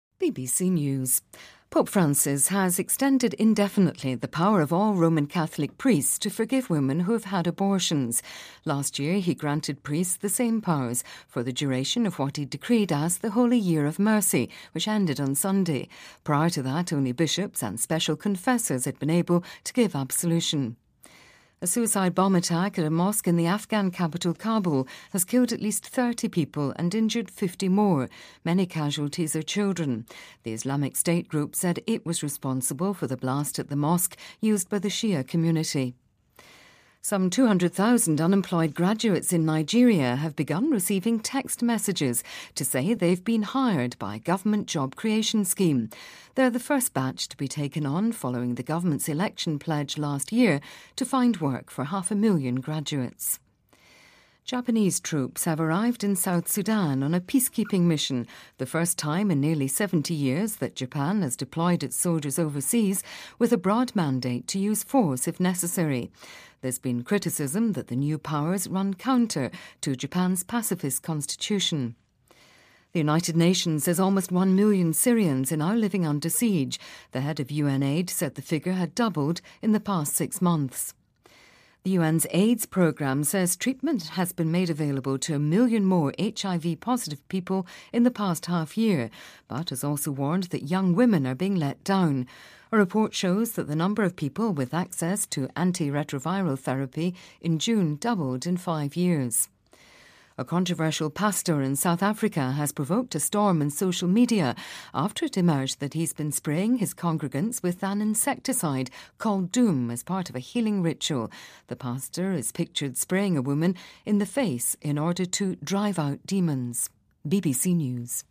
BBC news,日本军队抵达南苏丹参加维和任务